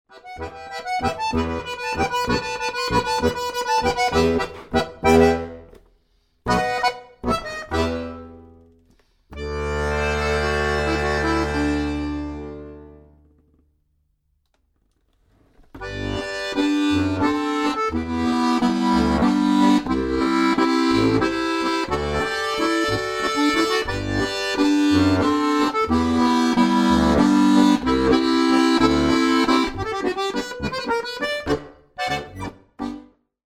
• für 4-reihige Harmonika